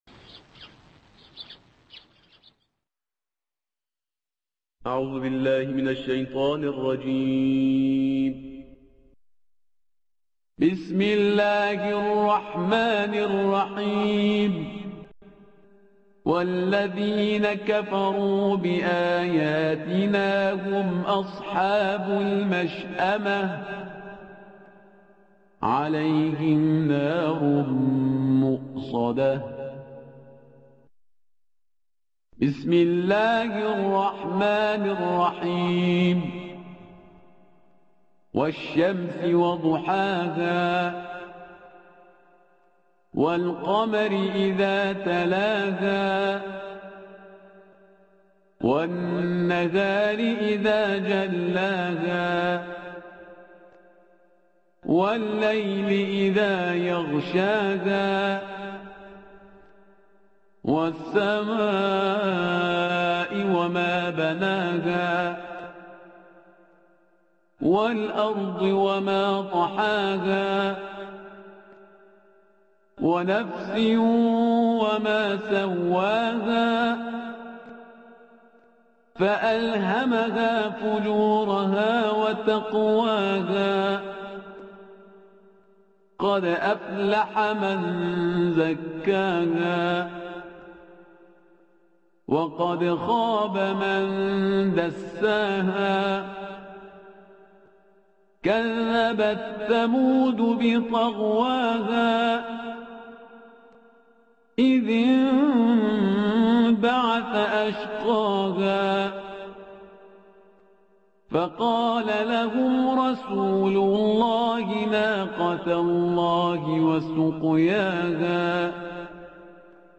صوت | ترتیل سوره شمس و لیل